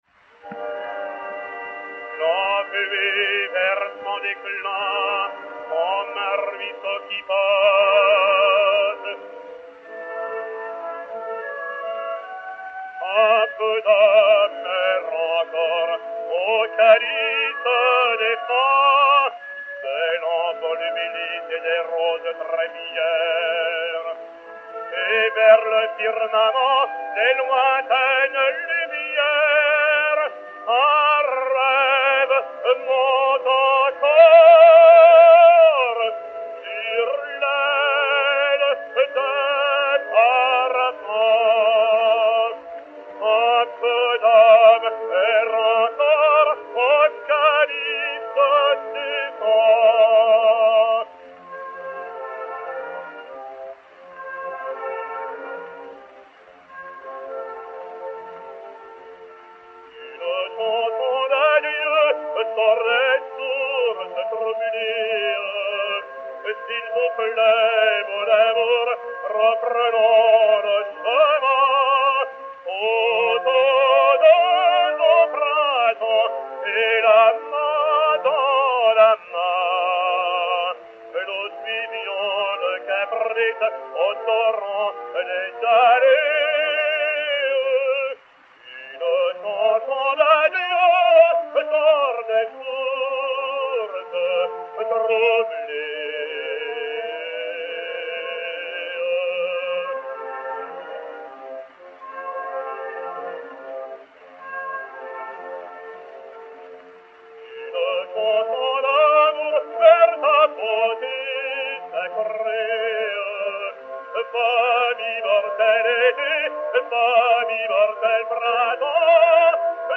Jean Noté, baryton, avec orchestre